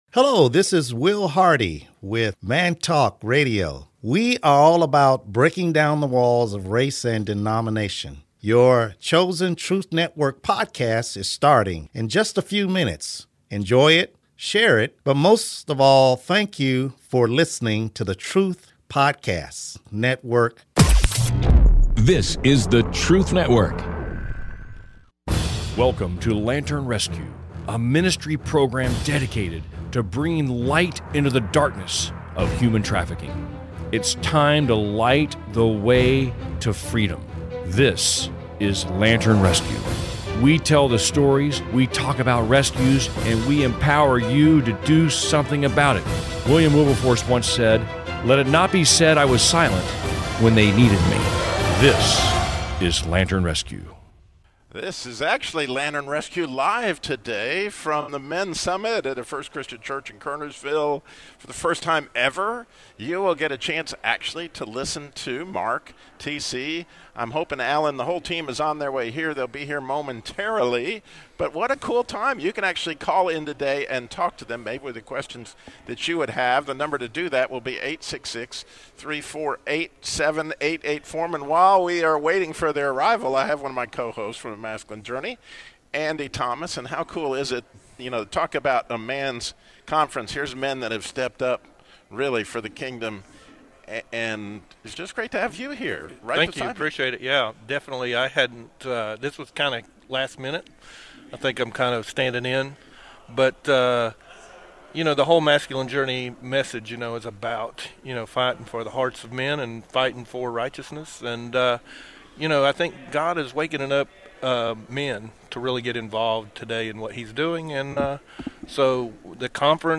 The Lantern Rescue team has been working tirelessly in overseas operations, and now they're finally BACK to share how God has been at work! They're at the 2021 Men's Summit at First Christian Church in Kernersville, NC, talking about their most recent mission in West Africa and how God has been moving through their rescue operations and fundraising efforts.